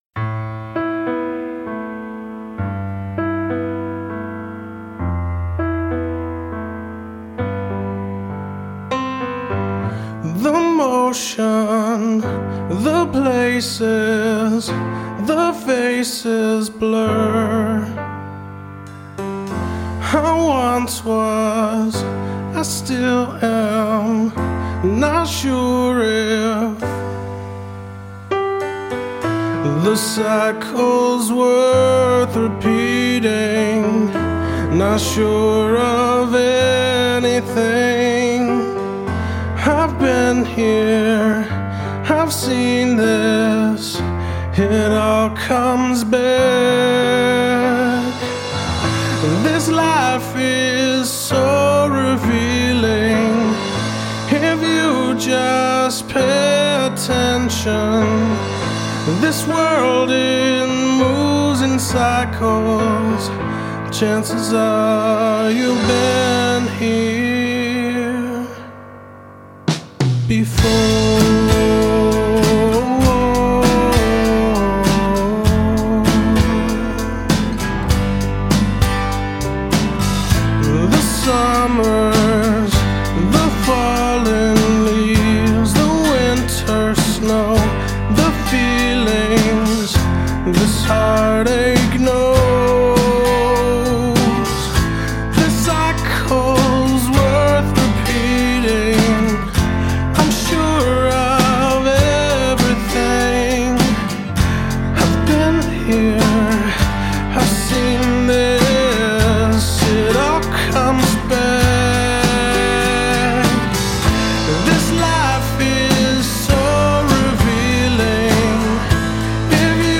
This song was originally written on guitar